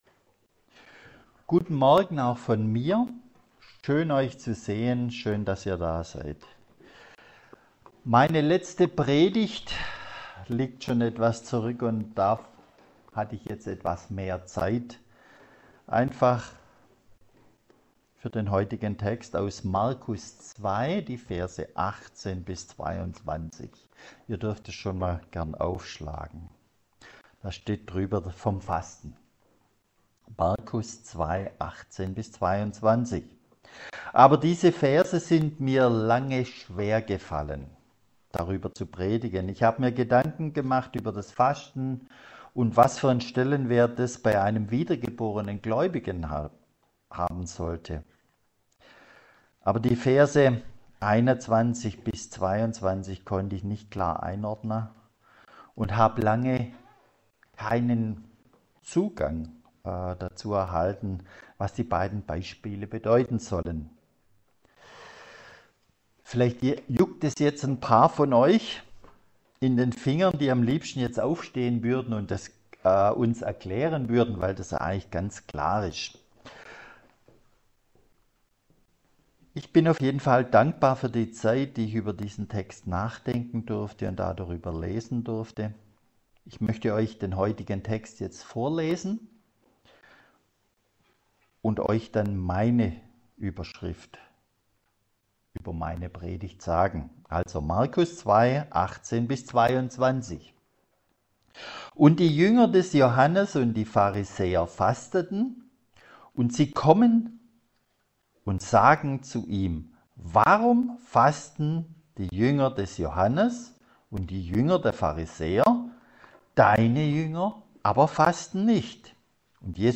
Was hatte es damals mit dem Fasten auf sich? 2. Was für eine Haltung und Meinung hatte Jesus zum Fasten? 3. Jesus lebt die Gnade Gottes und erklärt dadurch das Gesetz und den Weg zu Gott Predigtreihe: Markusevangelium Auslegungsreihe